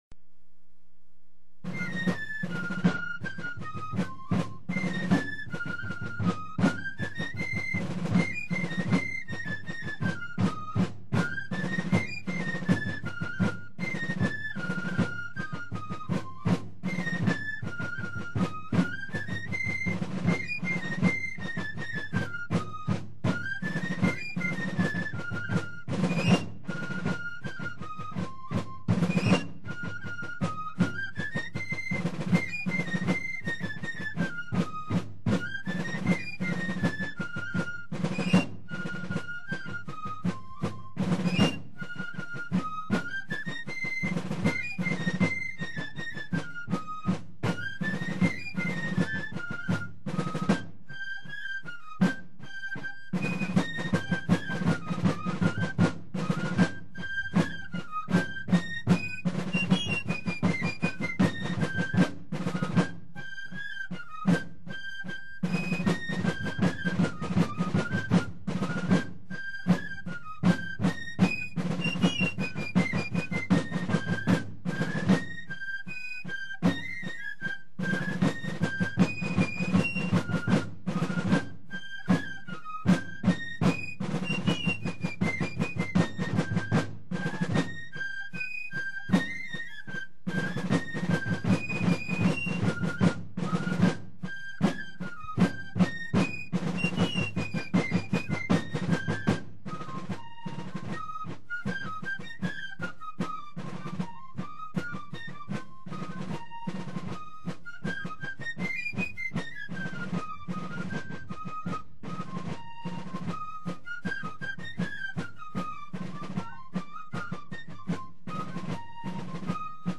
British martial music of the Napoleonic Wars
Fife
Drum Beating